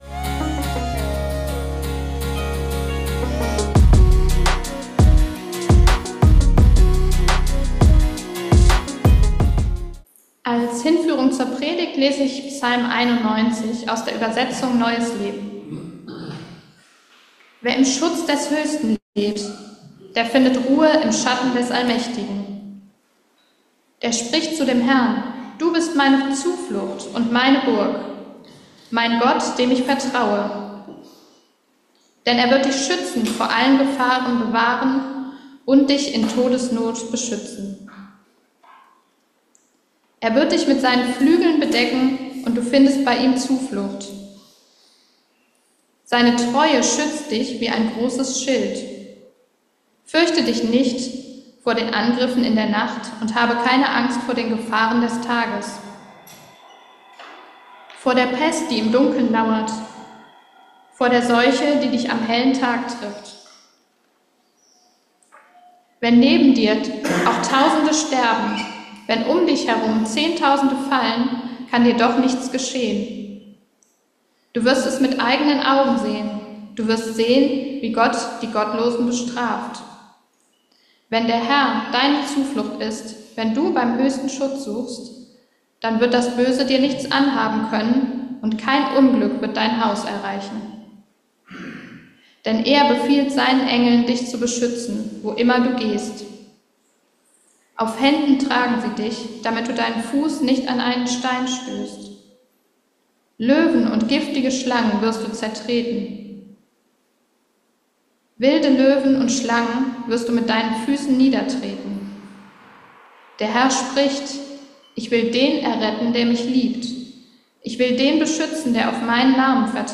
Zuversicht durch Gottvertrauen ~ Geistliche Inputs, Andachten, Predigten Podcast